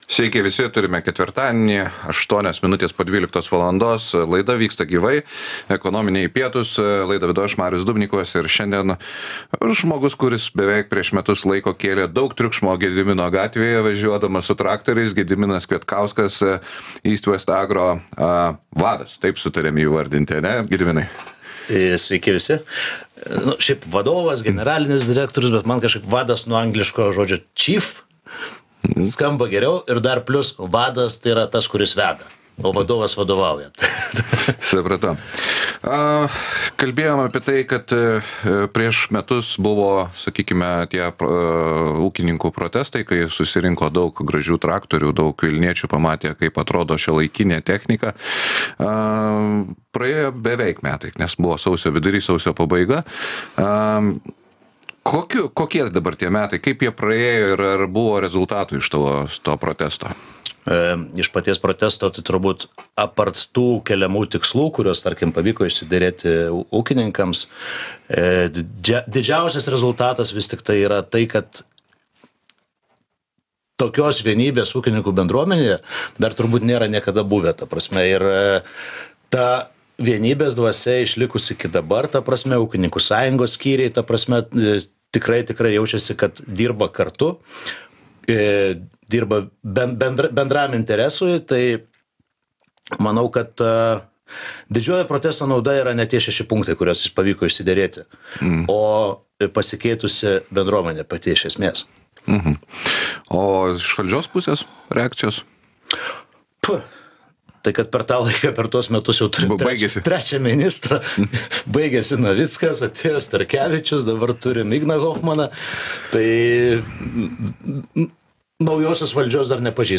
Laidos pašnekovas